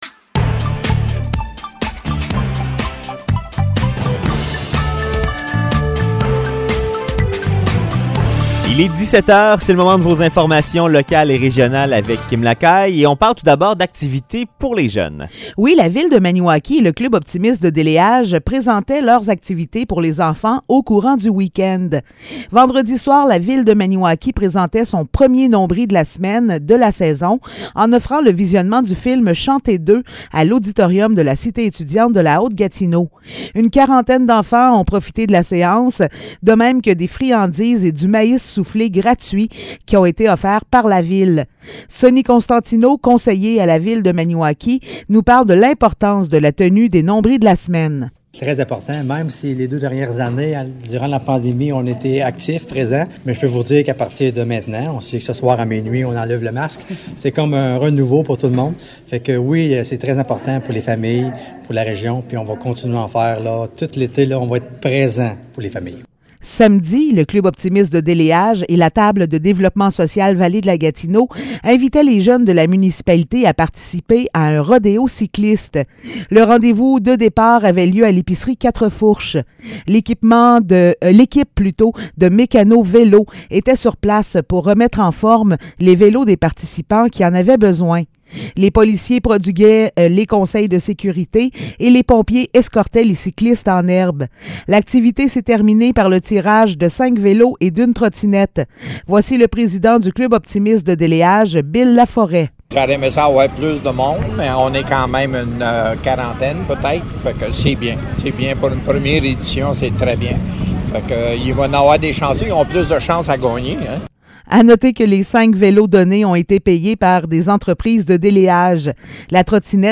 Nouvelles locales - 16 mai 2022 - 17 h